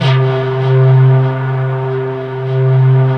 SYNPIPE C2-L.wav